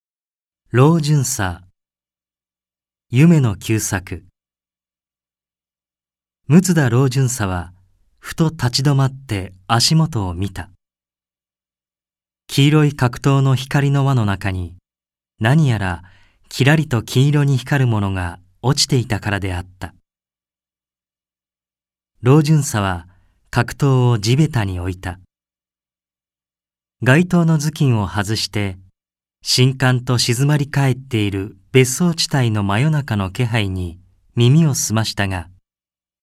朗読ＣＤ　朗読街道９５「老巡査・衝突心理」夢野久作
弊社録音スタジオ
朗読街道は作品の価値を損なうことなくノーカットで朗読しています。